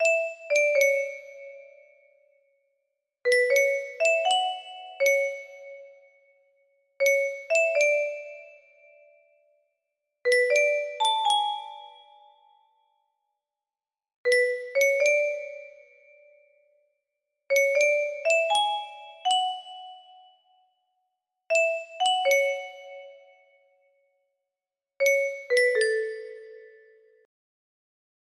Unknown Artist - Untitled music box melody
Full range 60